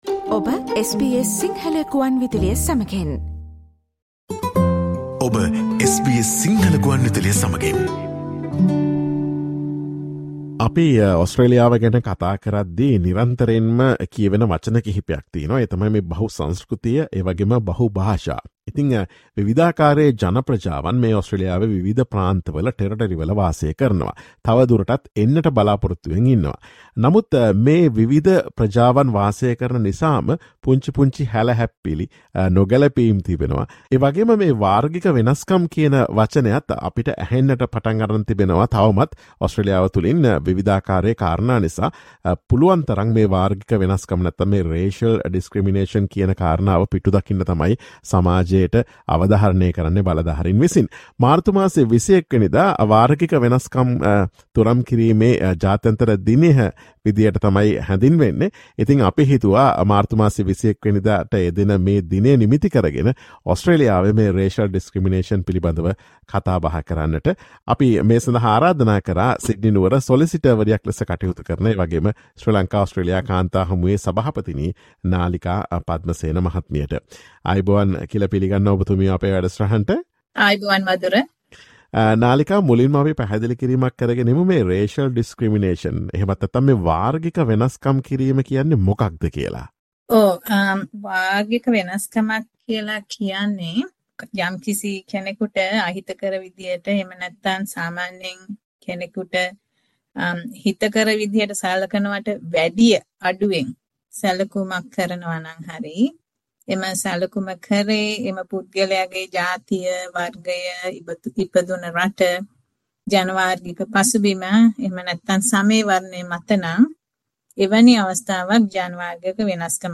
Listen to SBS Sinhala Radio's discussion on Australia's laws against racial discrimination and what action can be taken in Australia when faced with racial discrimination.